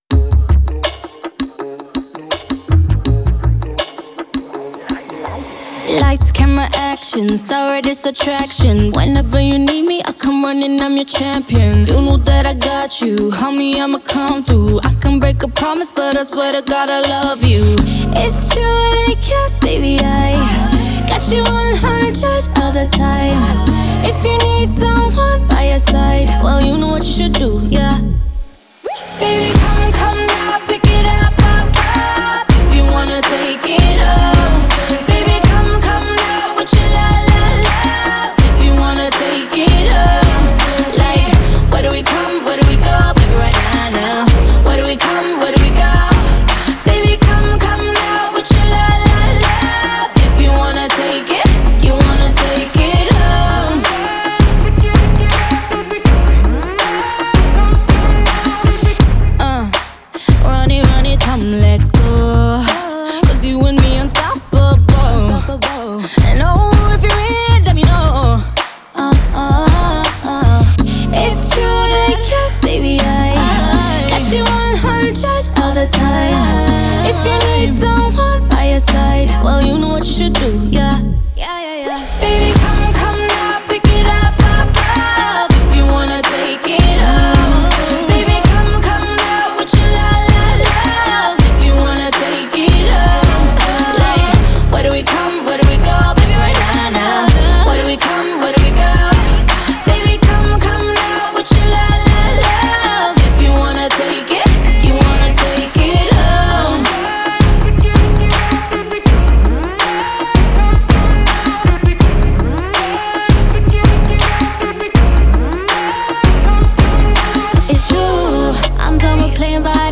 поп-песня
демонстрирует мощный вокал и искренность в исполнении